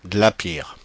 Ääntäminen
France (Paris): IPA: /ɡla.piʁ/